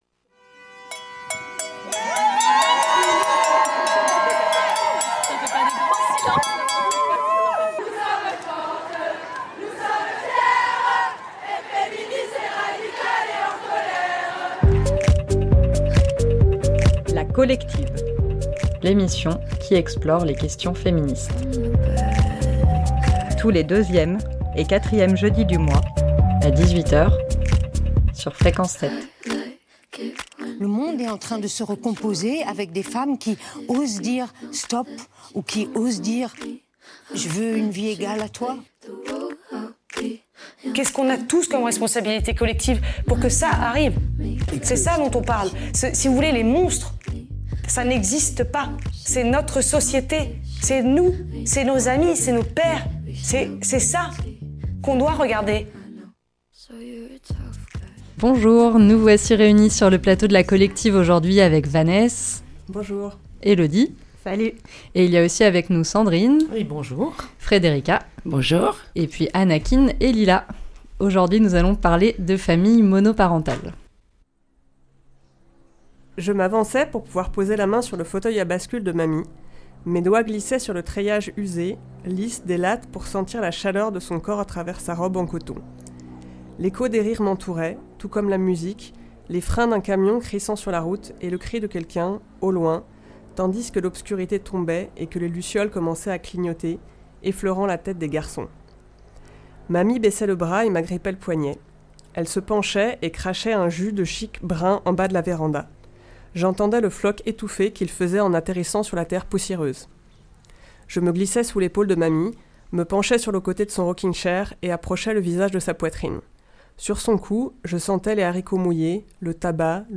Des témoignages, des entretiens, des reportages, des lectures et de la musique pour prendre conscience que les dominations sont multiples et que le féminisme peut transformer notre regard sur le monde et nous-même.
Présentation : Le collectif du 8 mars